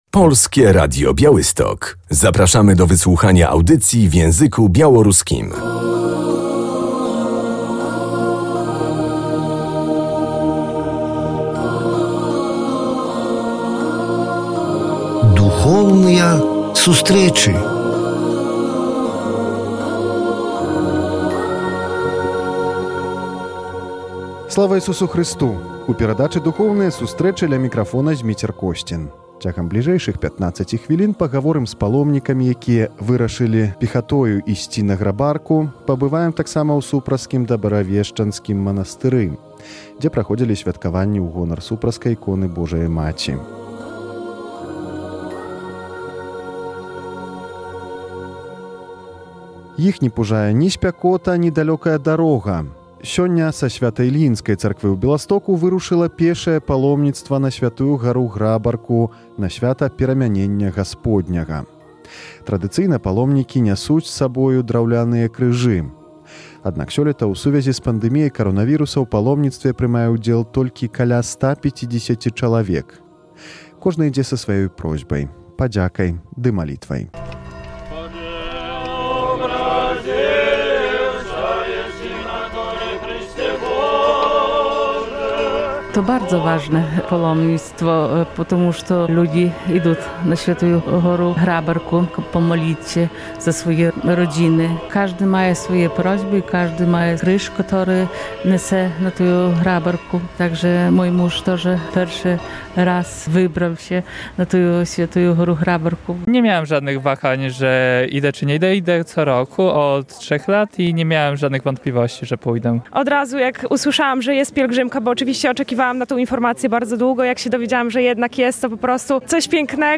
W audycji usłyszymy relację ze święta ku czci Supraskiej ikony Matki Bożej w monasterze w Supraślu.